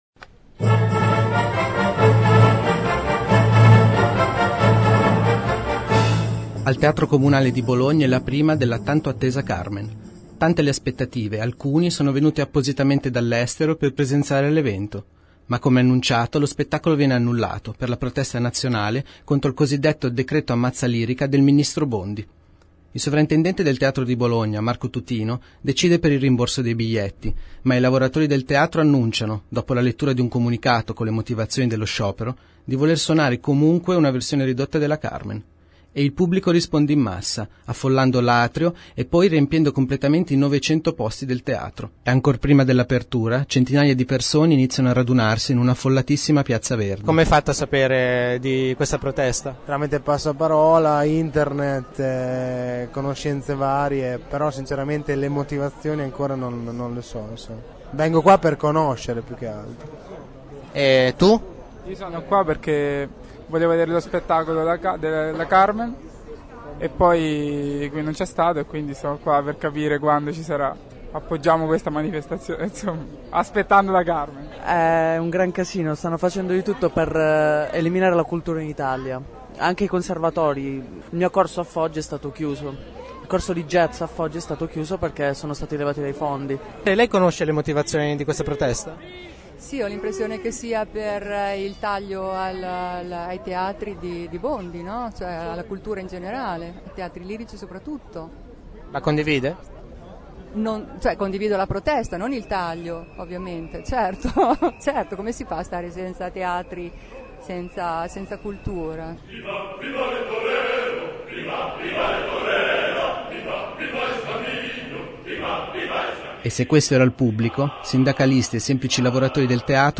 Ieri sera, l’iniziativa “Sciopero: teatro aperto” ha riscosso un grande successo di pubblico.
Ascolta lo speciale sulla serata